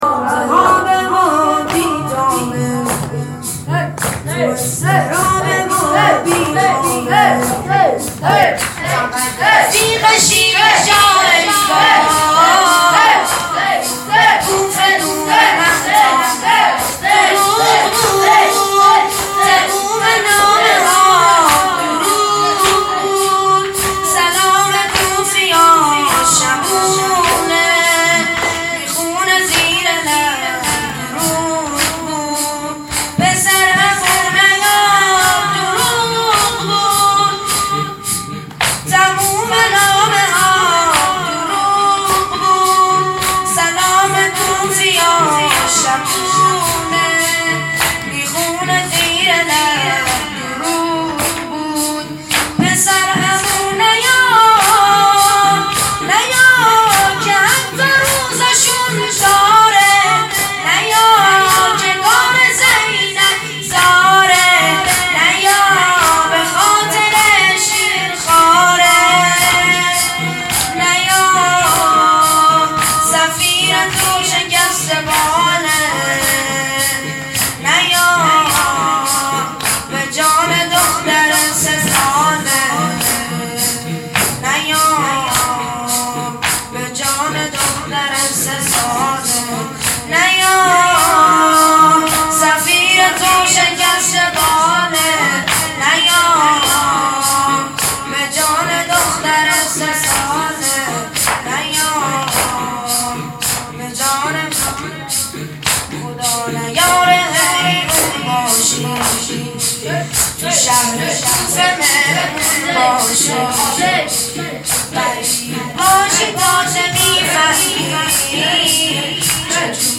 خیمه گاه - شجره طیبه صالحین - شب اول محرم _ زمينه _ تو التهابه و بيتابه